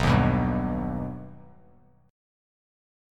Listen to B+ strummed